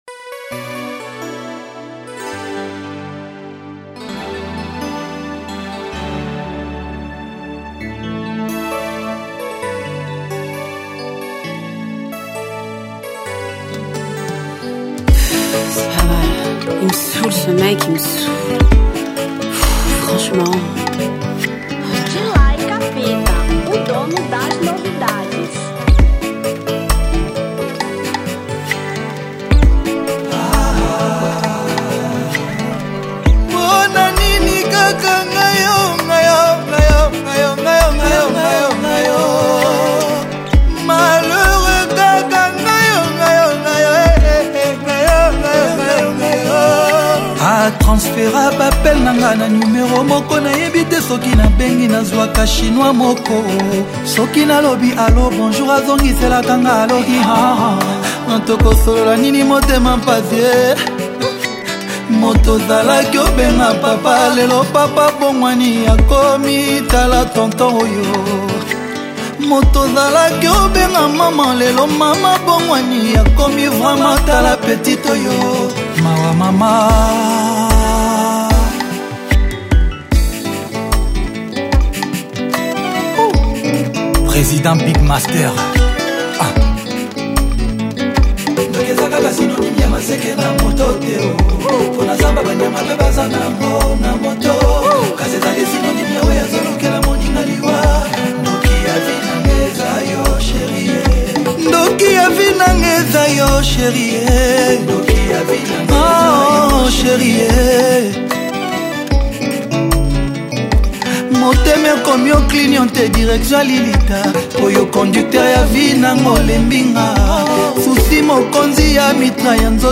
Rumba 2013